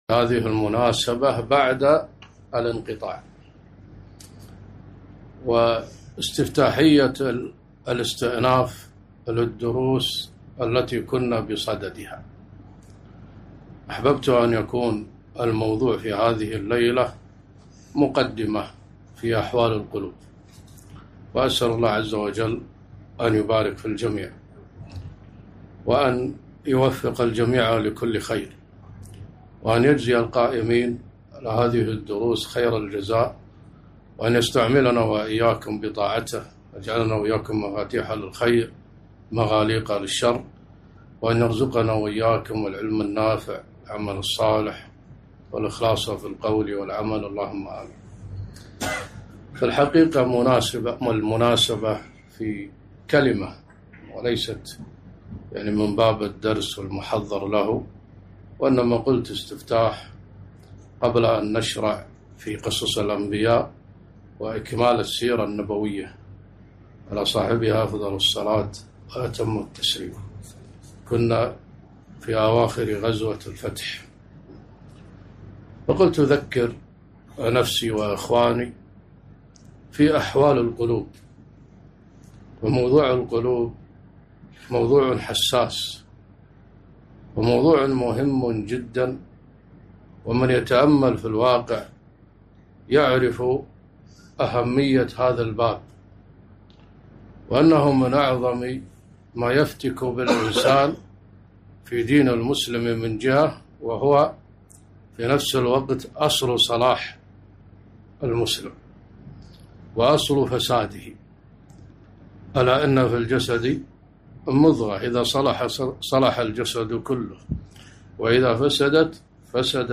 محاضرة - أحوال القلوب وأثرها على النفس